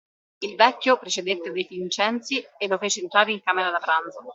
Read more Noun Verb Frequency A2 Hyphenated as pràn‧zo Pronounced as (IPA) /ˈpran.d͡zo/ Etymology Inherited from Latin prandium, whence also Dalmatian prinz and Romanian prânz.